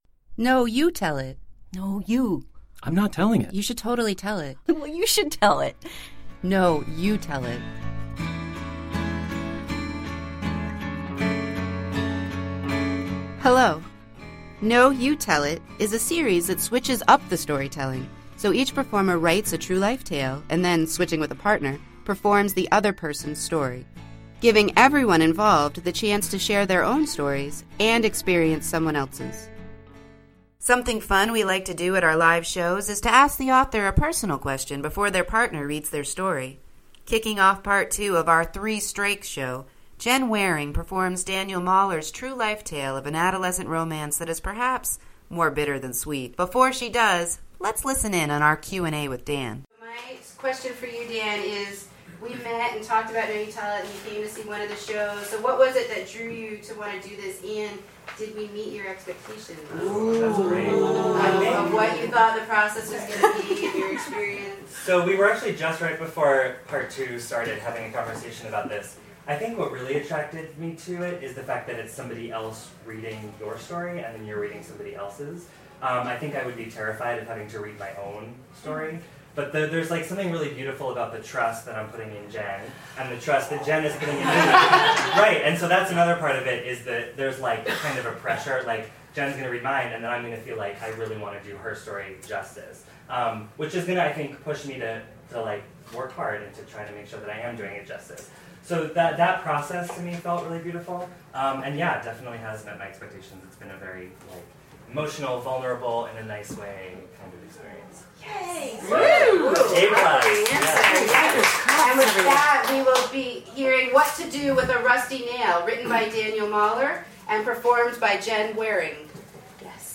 Switched-Up Storytelling Series and Podcast
Did you know that part of the fun at our lives shows is to ask the author a personal question before their partner performs their story?